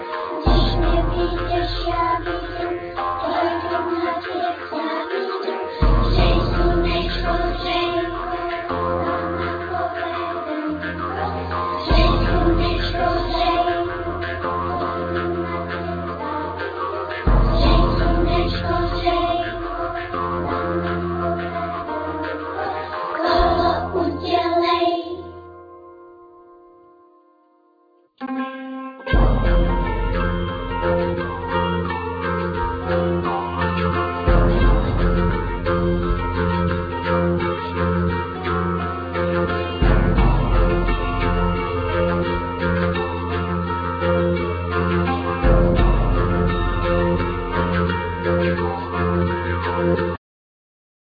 Flute,Voice
Violin,Viola,Voice
Double bass
Darbuka,Djembe
Sitar,Voice